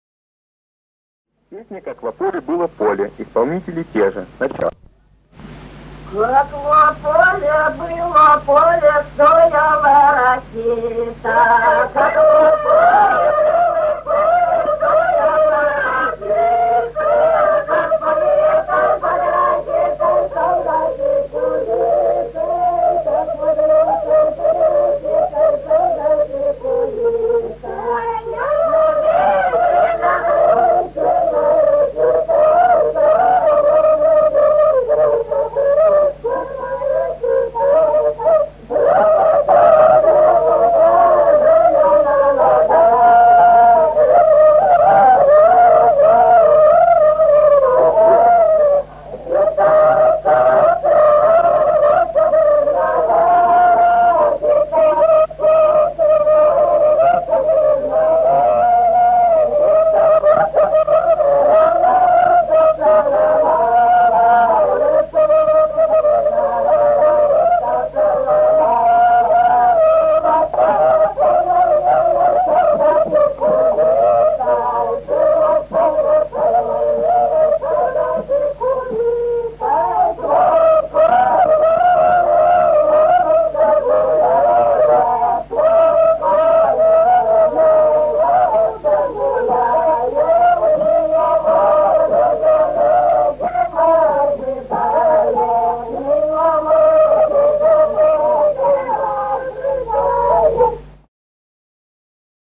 Русские народные песни Владимирской области 038. Как во поле было, поле (плясовая). с. Михали Суздальского района Владимирской области.